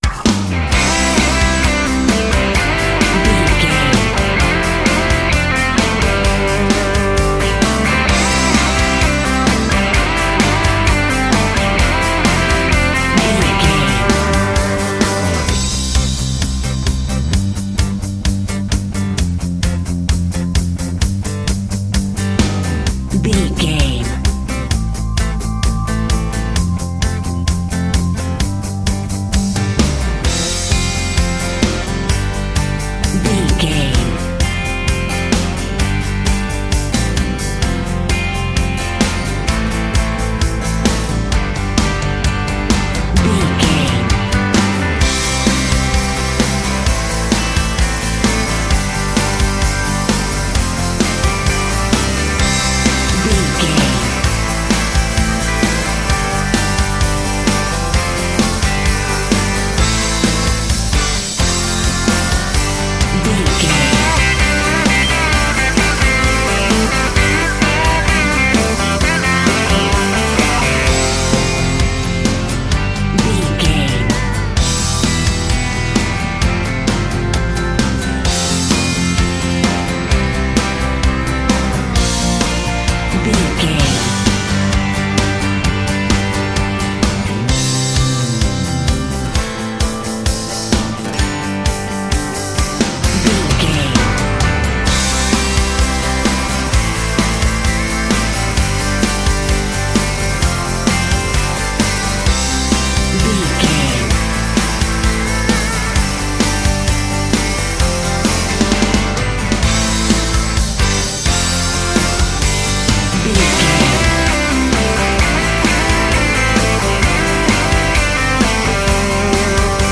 Ionian/Major
electric guitar
drums
bass guitar